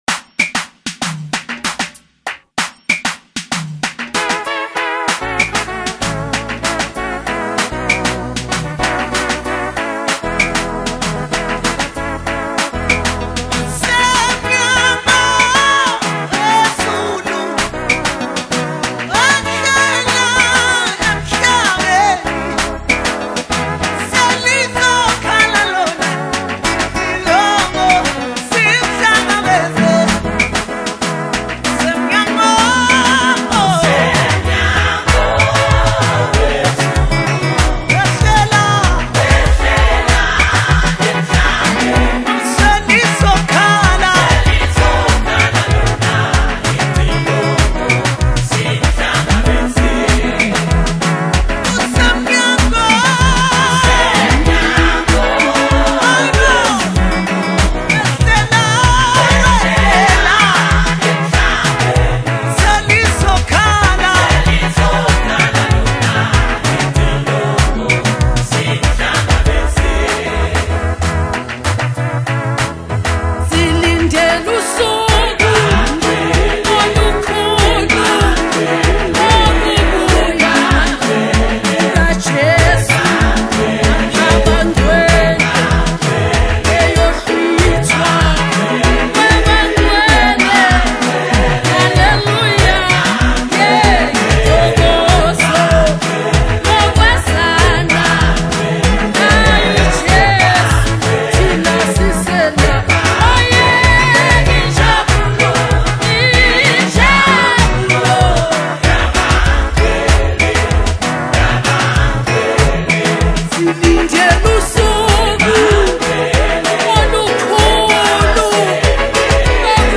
South African Gospel